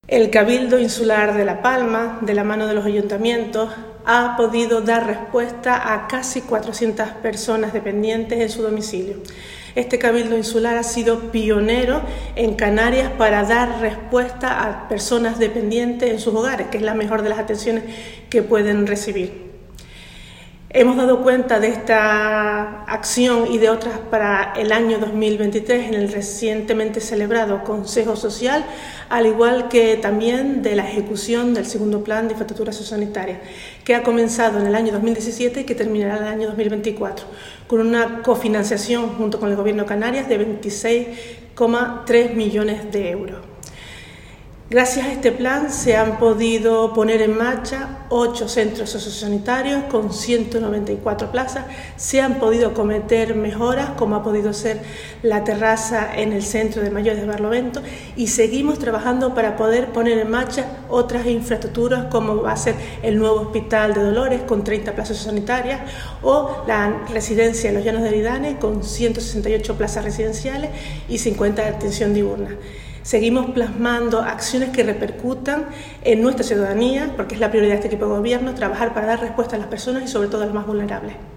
Declaraciones audio Nieves Hernández Consejo Social.mp3